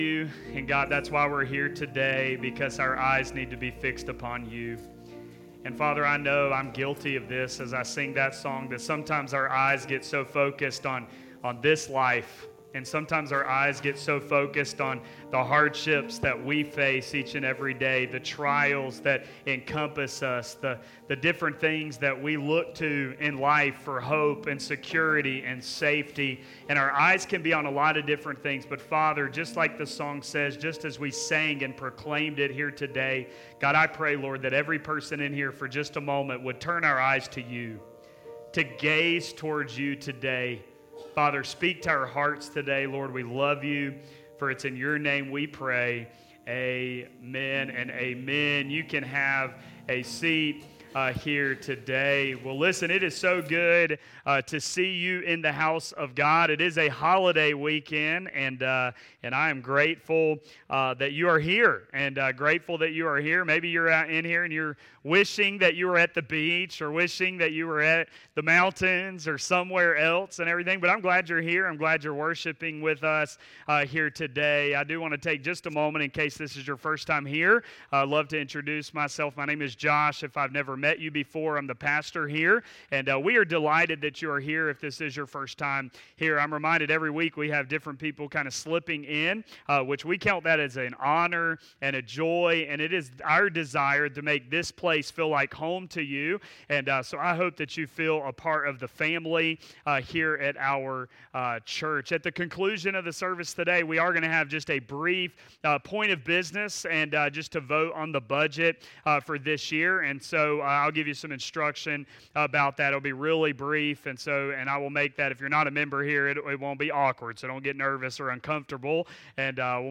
The Greatest Sermon Ever